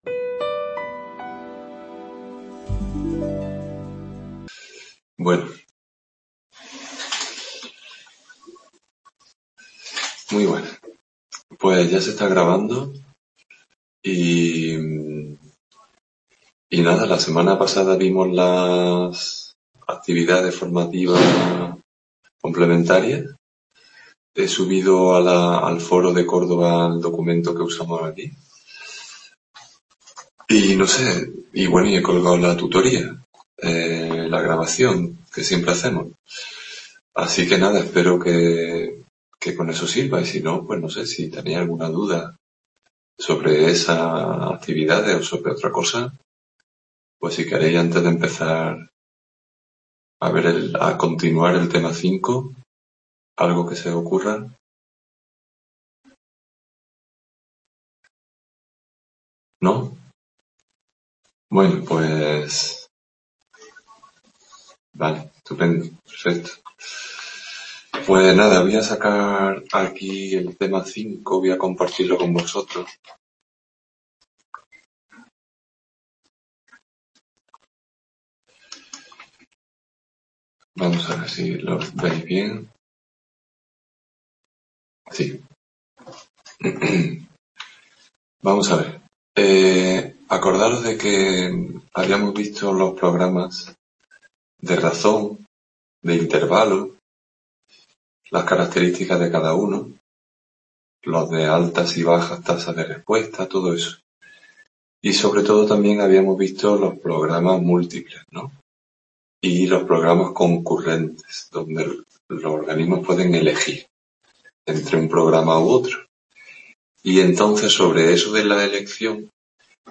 Tutoría de Psicología del Aprendizaje en Córdoba Tema 5.